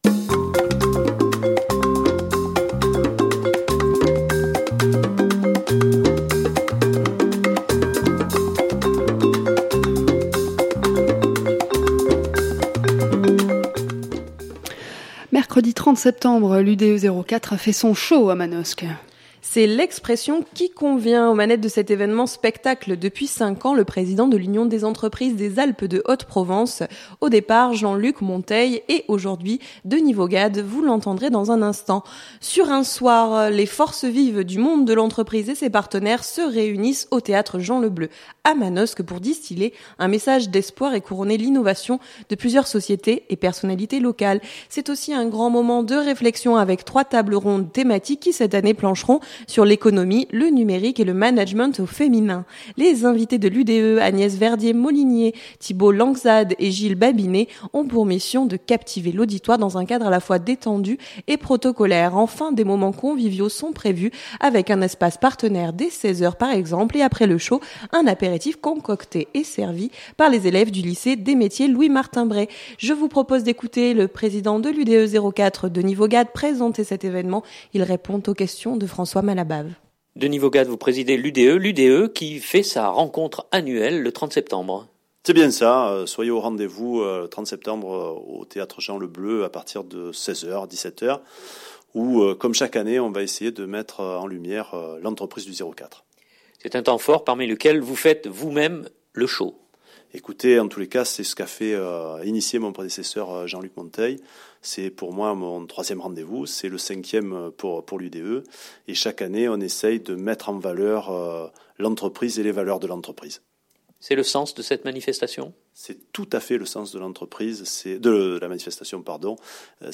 Il répond aux questions